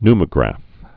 (nmə-grăf, ny-) also pneu·mat·o·graph (n-mătə-grăf, ny-)